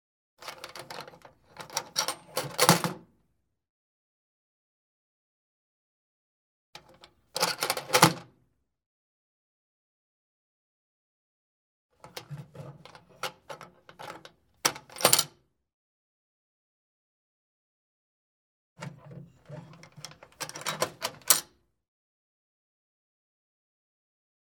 Metal Theatre Film Can Open Latch Lid Sound
household
Metal Theatre Film Can Open Latch Lid